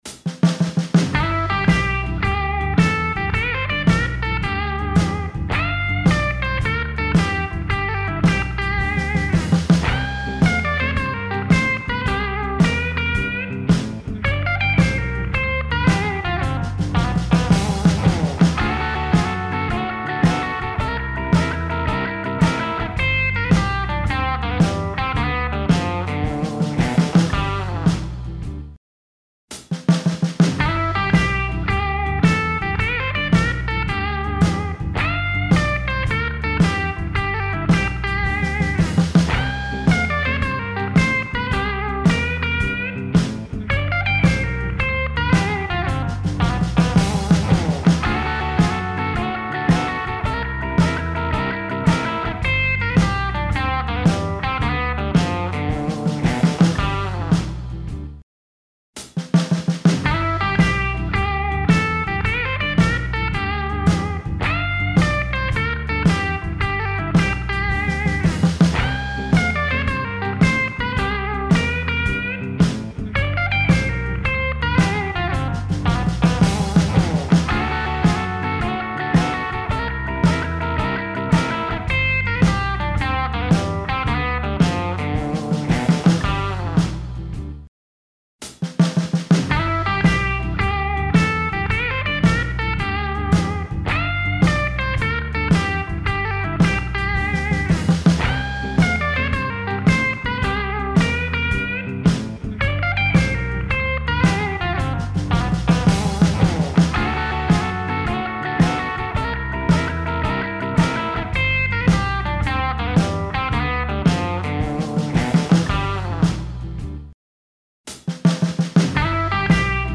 Intermediate to Advanced Rhythm and Soloing Techniques for Electric Blues!